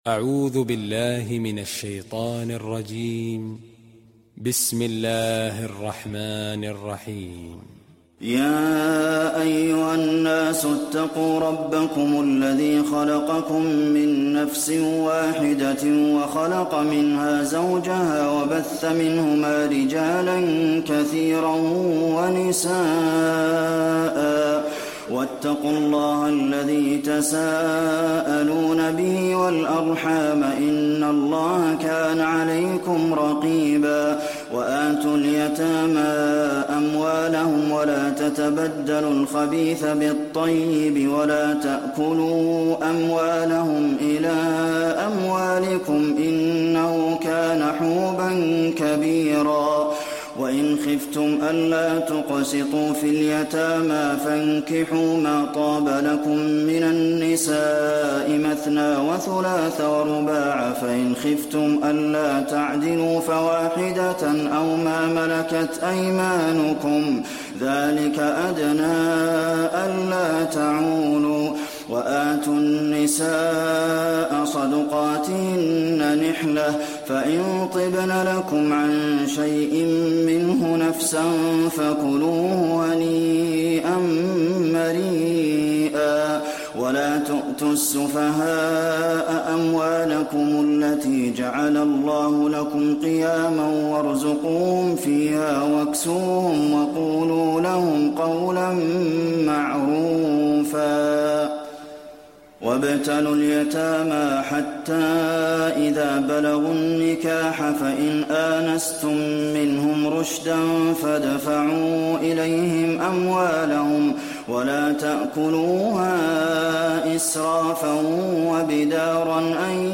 المكان: المسجد النبوي النساء